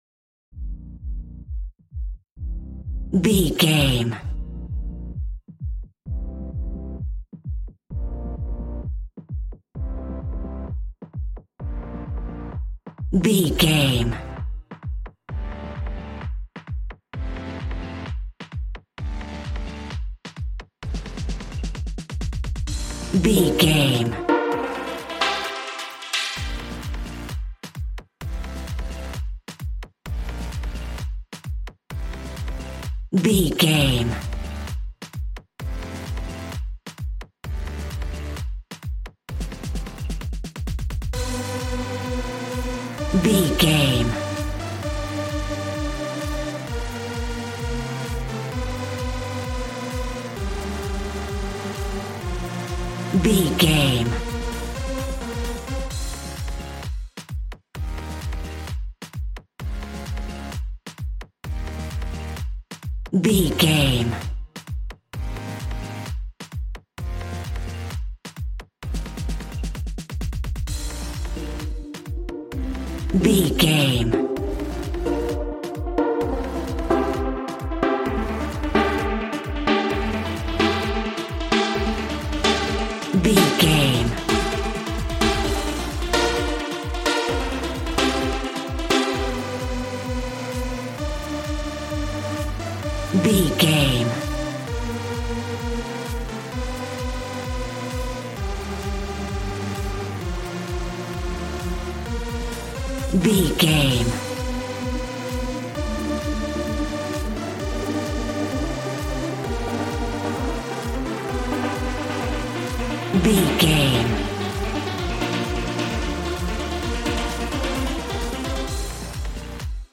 Aeolian/Minor
Fast
groovy
energetic
synthesiser
drums